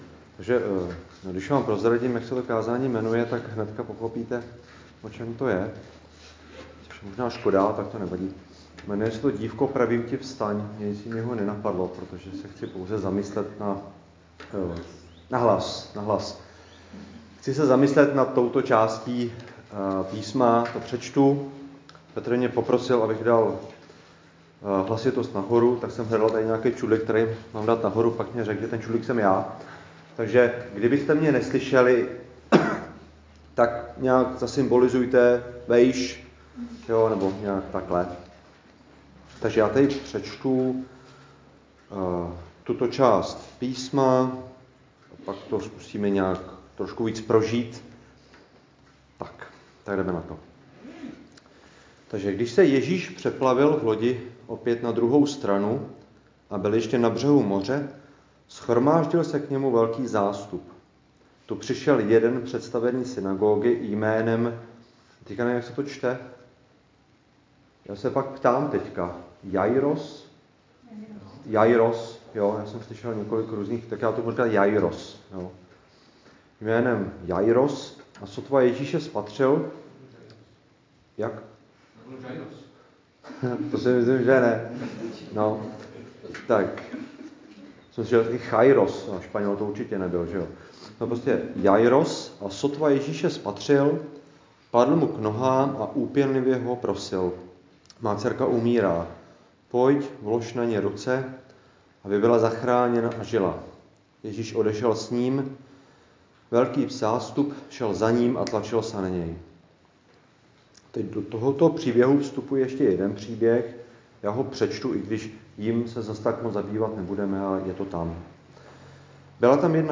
Křesťanské společenství Jičín - Kázání 9.5.2021